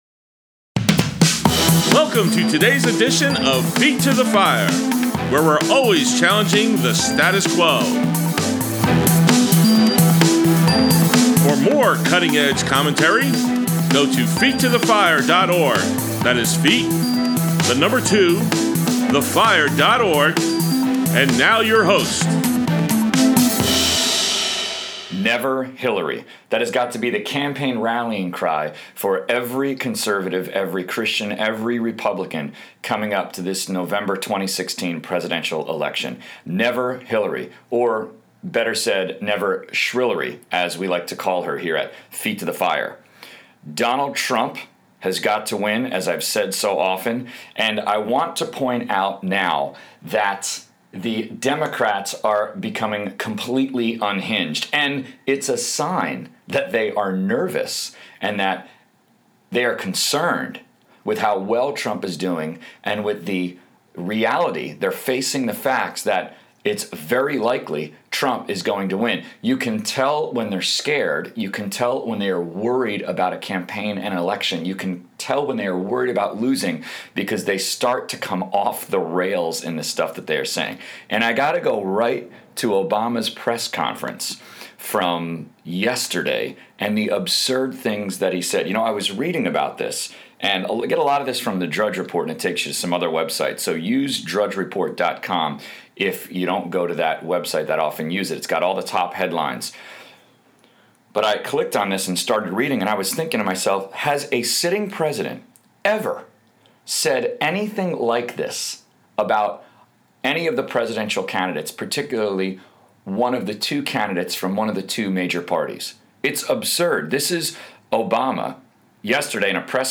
| Feet to the Fire Politics: Conservative Talk Show